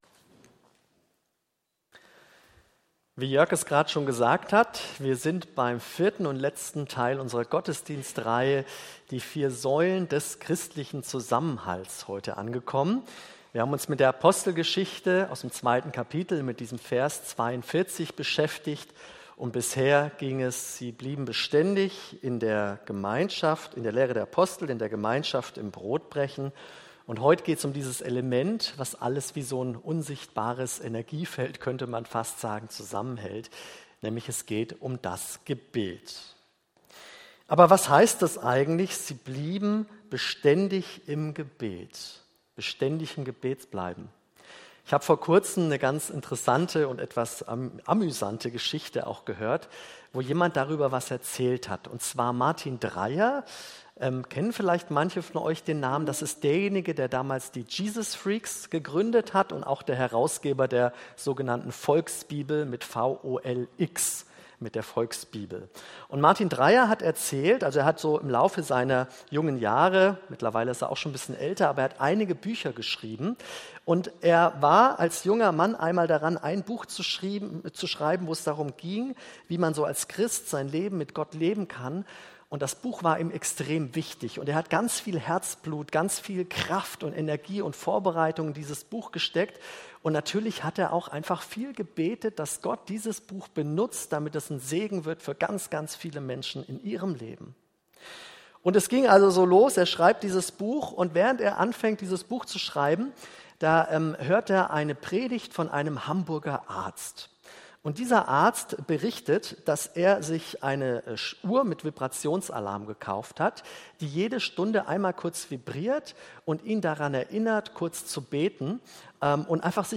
Predigt am Sonntag